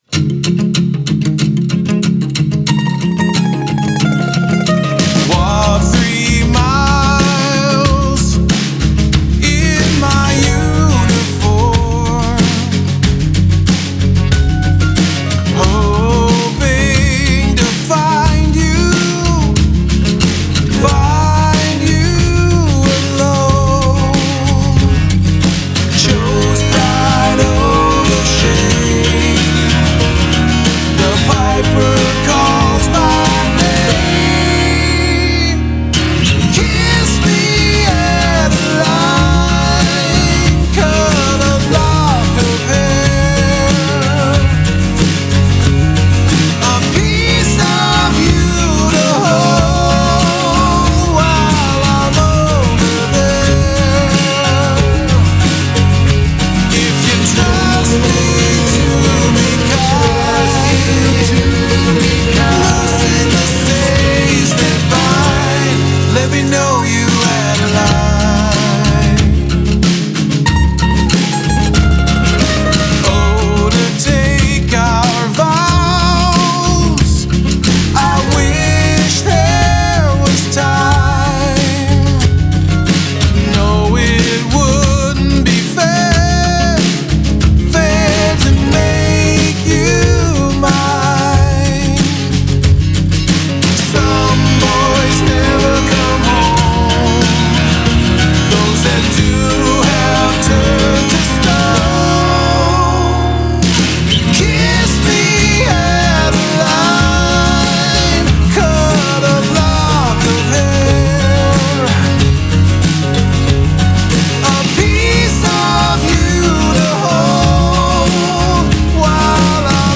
an eccentric folk/roots trio from Estevan
mandolin, electric guitar, bass, percussion, vocals
acoustic and electric guitar, vocals